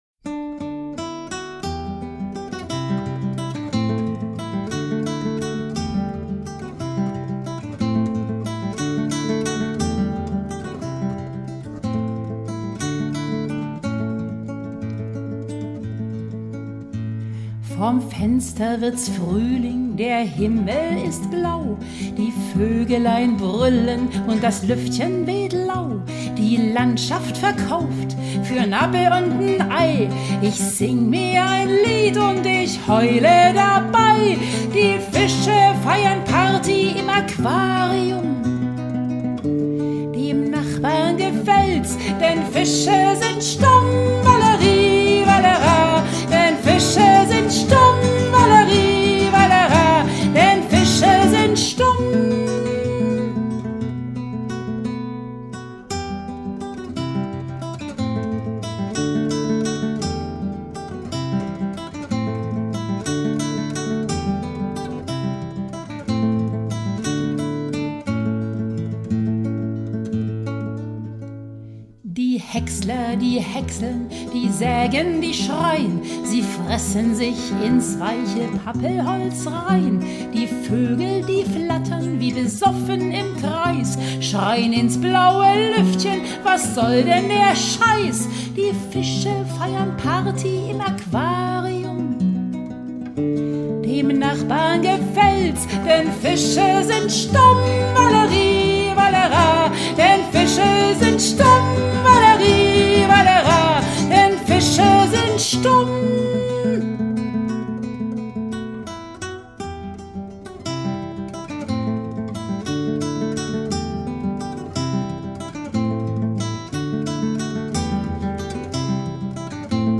Lieder
Gesang, Basskantele, Synthesizer